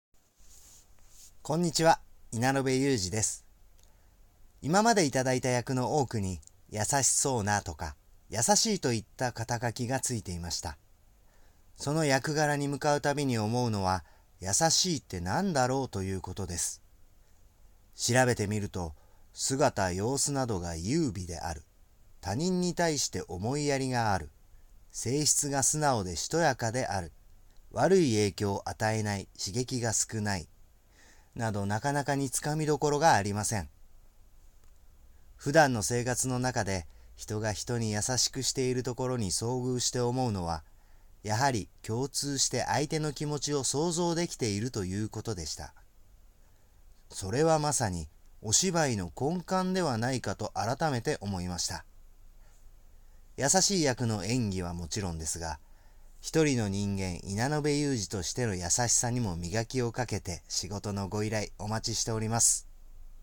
出⾝地・⽅⾔ 兵庫県・但馬弁
ボイスサンプル